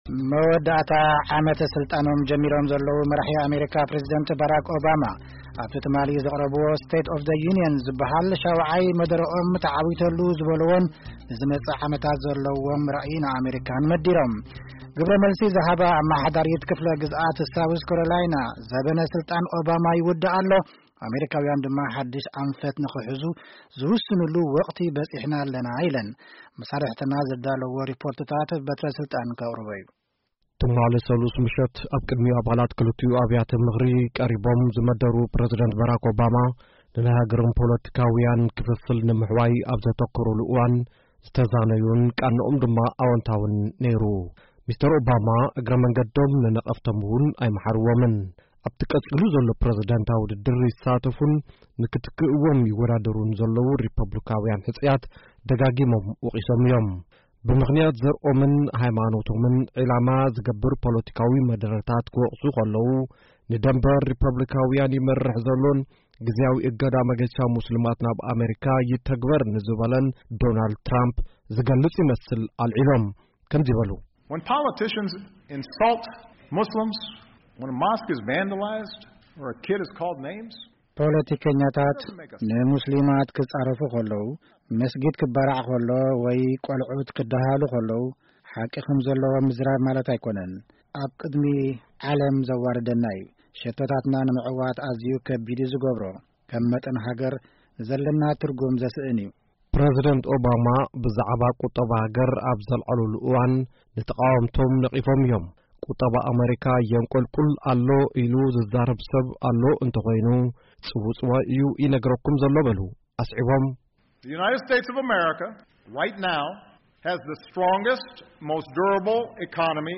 ሙሉእ ሪፖርት መደረ ፕረዚደንት ባራክ ኦባማን ካልኦትን